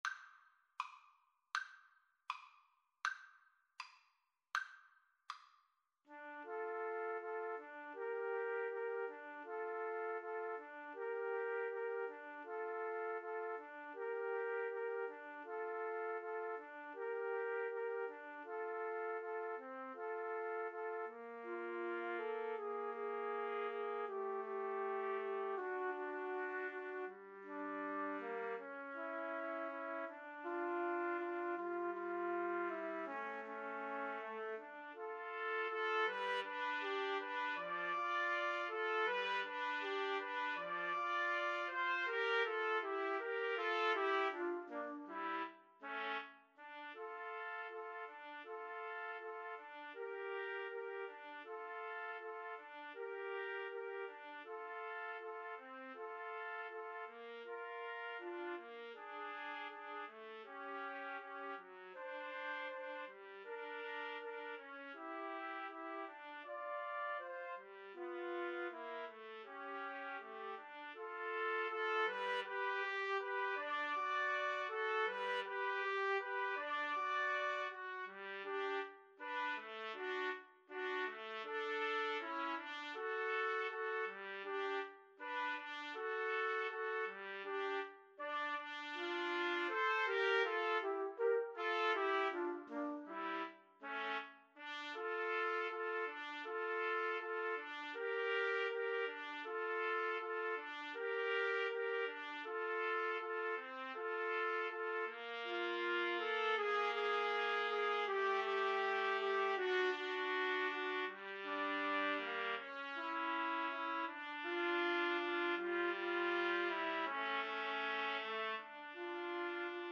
~ = 100 Andante
Classical (View more Classical Trumpet Trio Music)